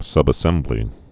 (sŭbə-sĕmblē)